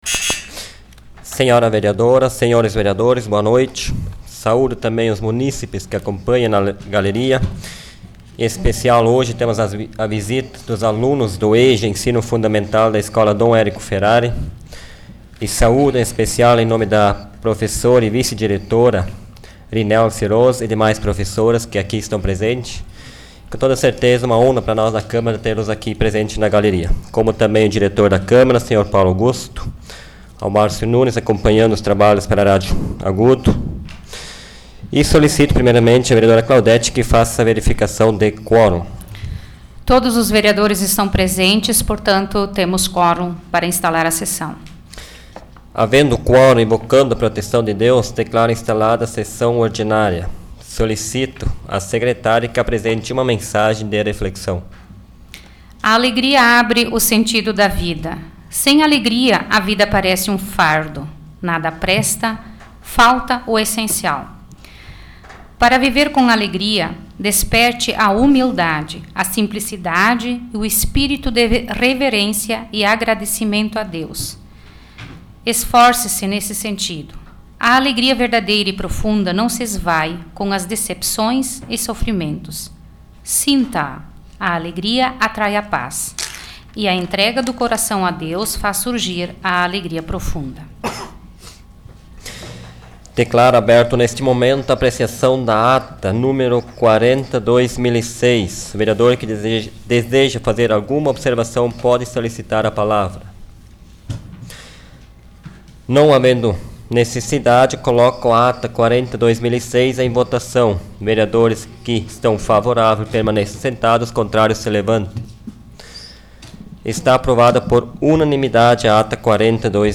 Áudio da 66ª Sessão Plenária Ordinária da 12ª Legislatura, de 23 de outubro de 2006